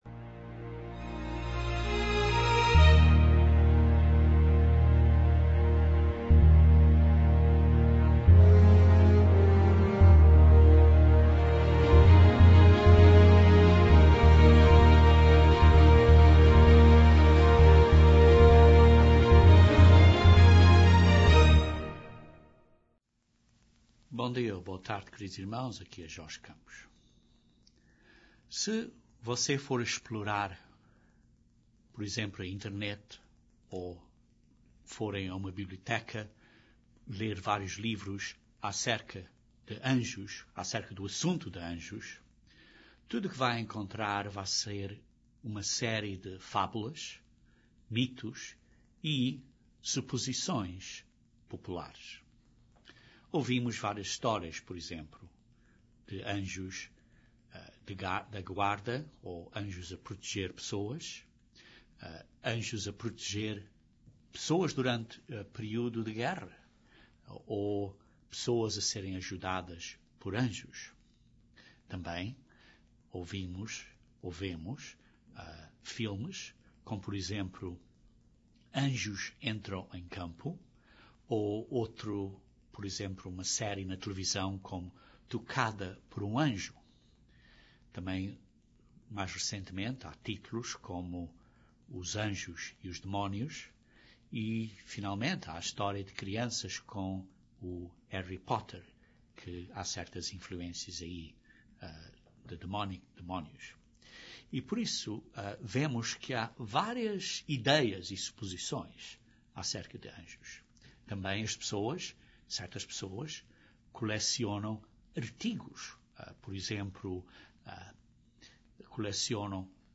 Este estudo bíblico explora o que a Bíblia diz acerca de anjos, quem são, o que podem fazer e como nos devemos conduzir por causa dos anjos